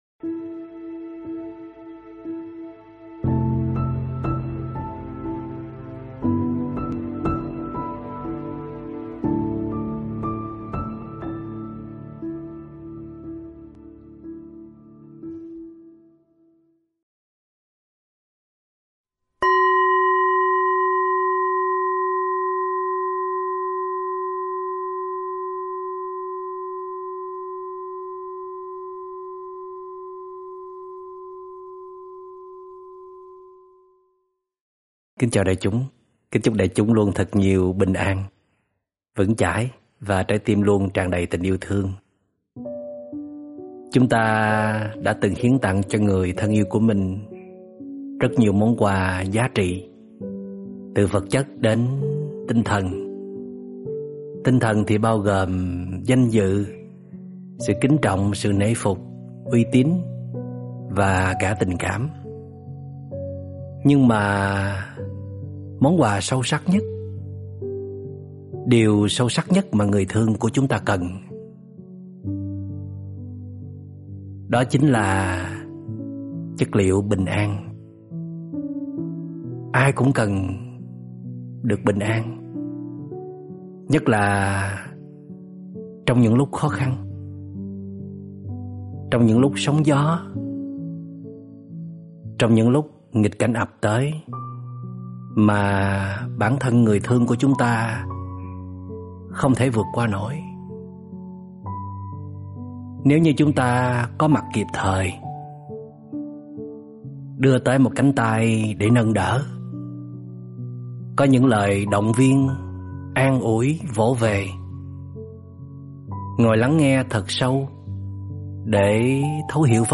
Pháp âm Điều tốt đẹp nhất cho nhau được Trích Radio: CHỈ TÌNH THƯƠNG Ở LẠI